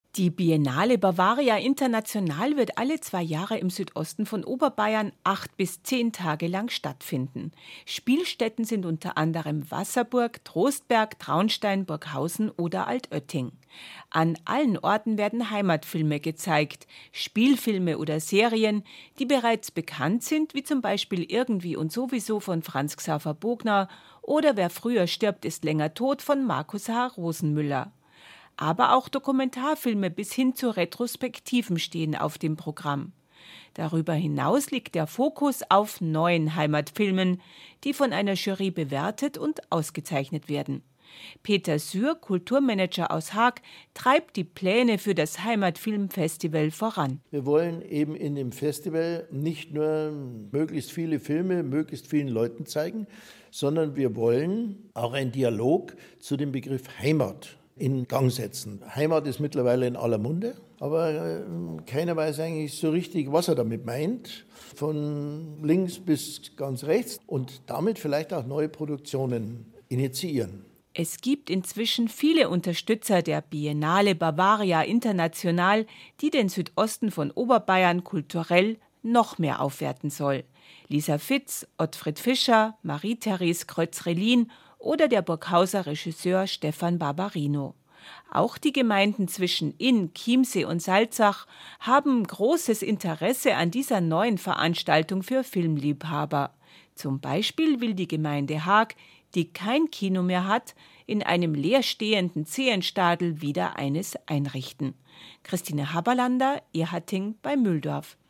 BR Radio – B5 aktuell – Die Kultur  – Beitrag